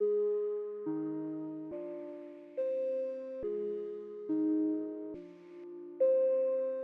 描述：快乐
Tag: 140 bpm Rap Loops Piano Loops 1.15 MB wav Key : Unknown